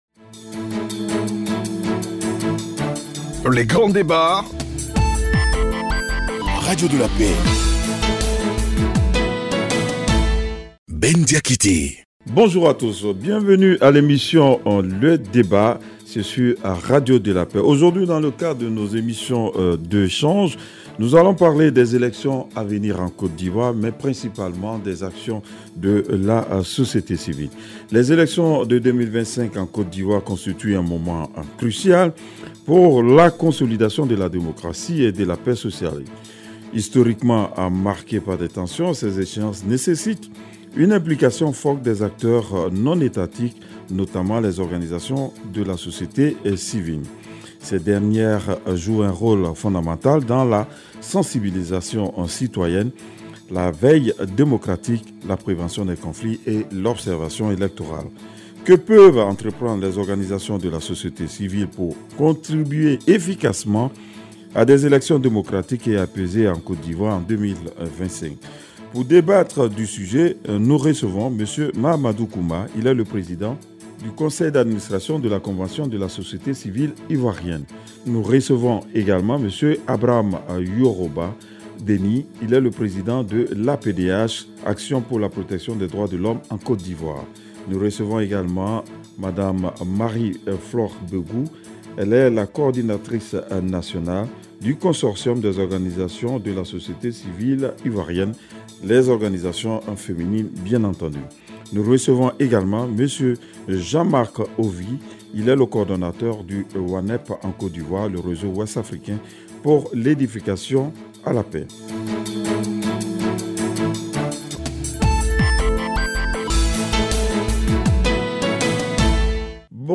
debat-la-societe-civile-et-lelection-presidentielle-2025.mp3